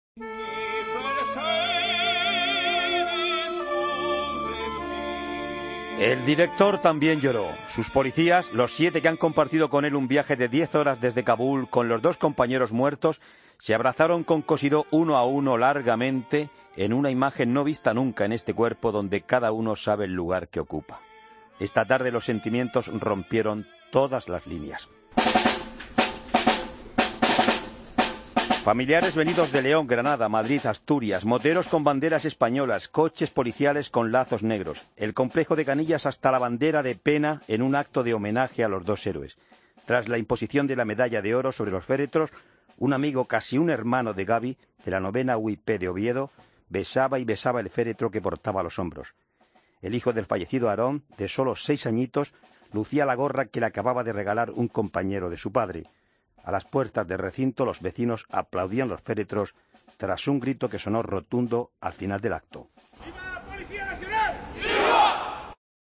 Reportaje: Funeral de los policías fallecidos en el atentado de Kabul